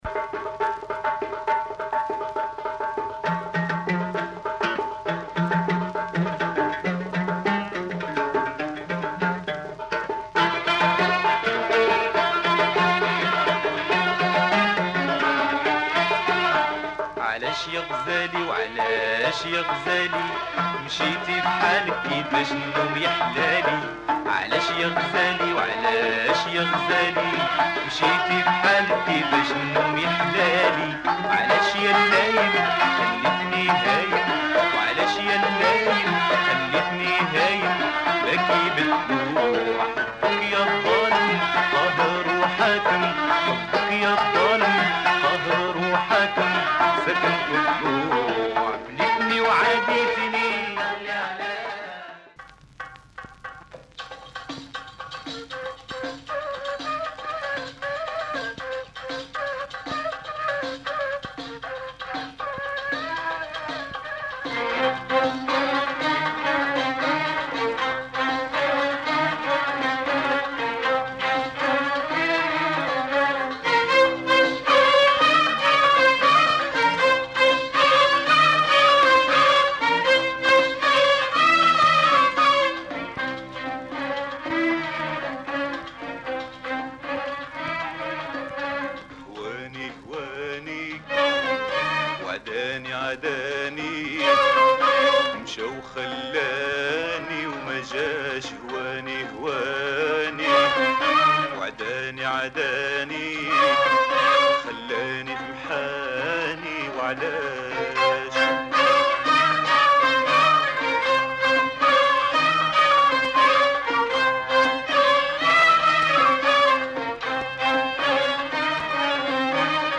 Moroccan Mizrahi